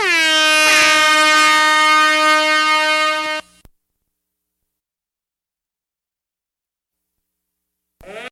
Play, download and share FOGHORN22321 original sound button!!!!
foghorn22321.mp3